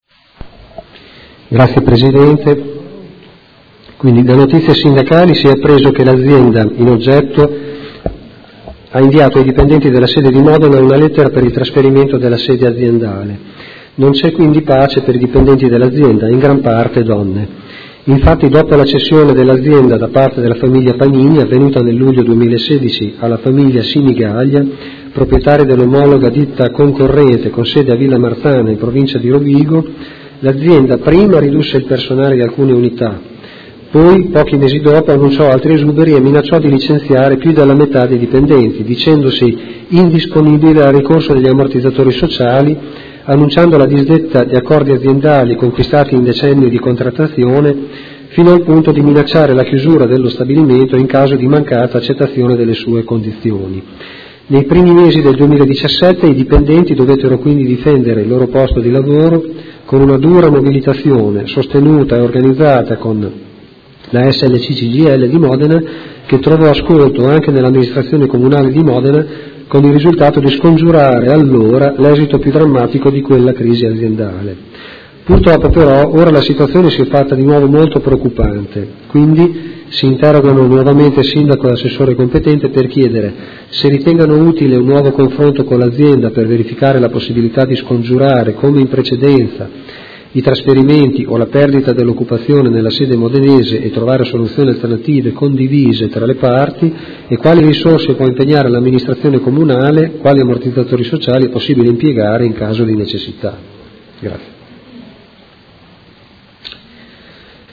Seduta del 22/11/2018. Interrogazione dei Consiglieri Malferrari e Trande (Art1-MDP/Per Me Modena) avente per oggetto: La Edis comunica ai dipendenti il trasferimento della sede aziendale da Modena a Villa Marzana (Rovigo)